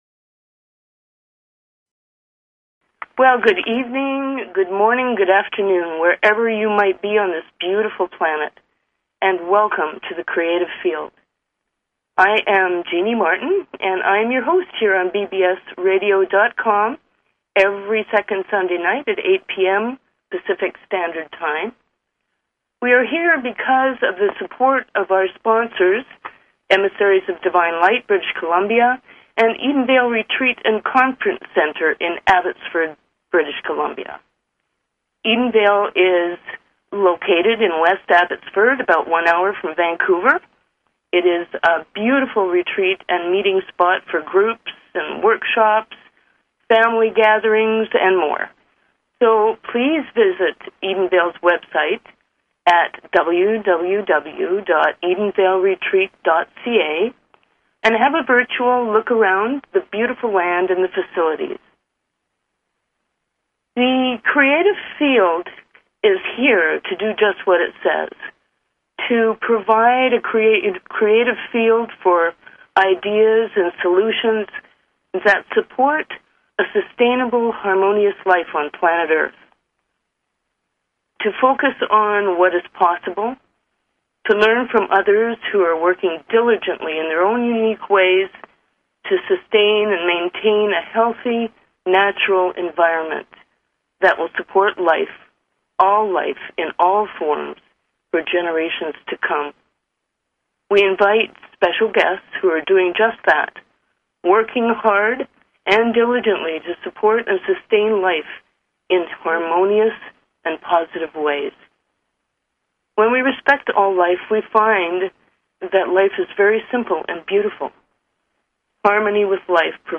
Talk Show Episode, Audio Podcast, The_Creative_Field and Courtesy of BBS Radio on , show guests , about , categorized as